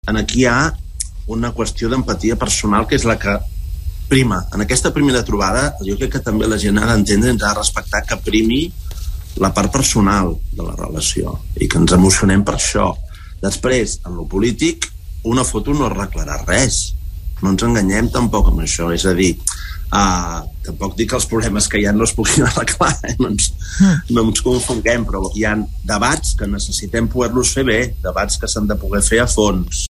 Toni Comín ha estat entrevistat avui a Catalunya Ràdio.